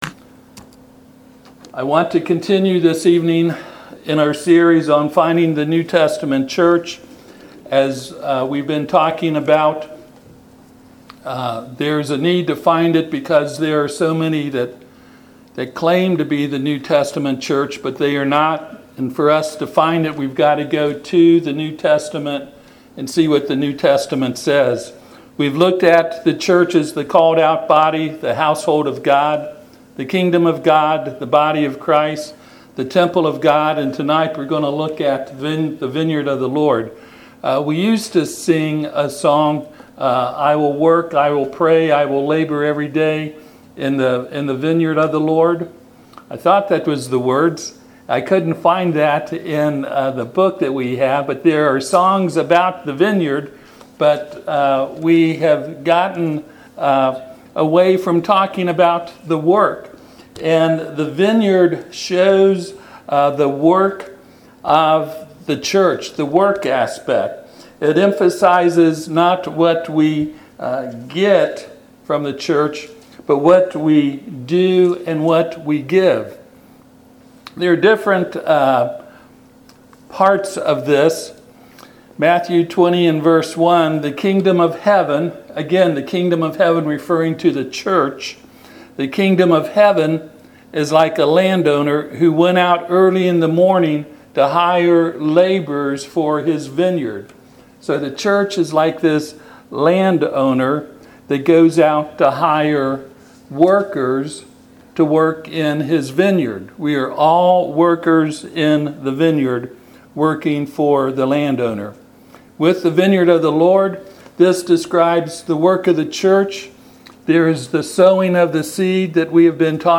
Passage: Matthew 20:1-16 Service Type: Sunday PM